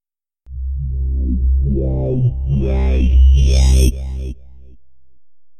Robotic Transition Sound Effect Free Download
Robotic Transition